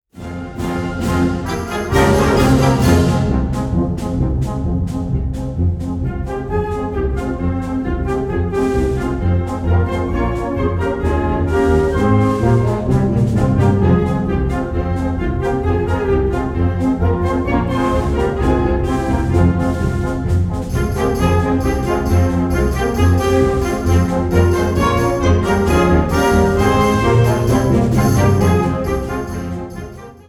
Categorie Harmonie/Fanfare/Brass-orkest
Subcategorie Concertmuziek
Bezetting Ha (harmonieorkest); YB (jeugdorkest)